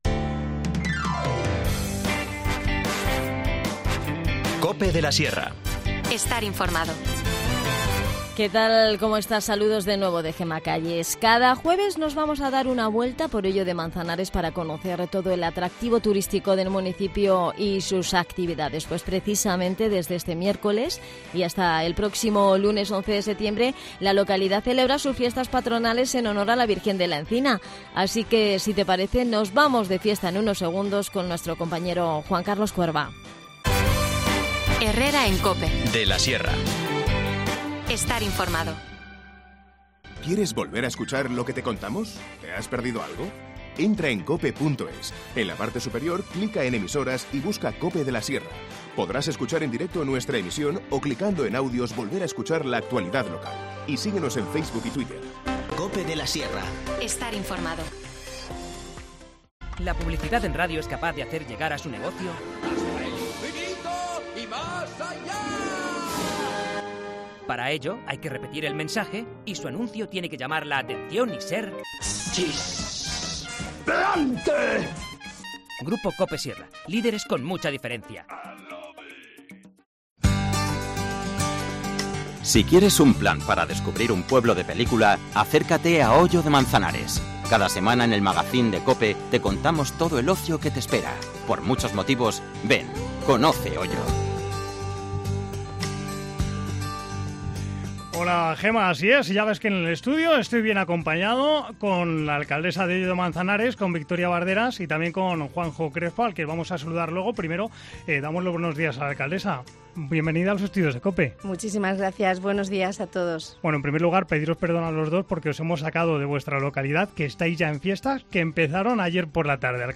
Recibimos la visita de la alcaldesa Hoyo de Manzanares, Victoria Barderas y del concejal de Festejos, Juan Jose Crespo para que nos cuenten todo lo que han preparado.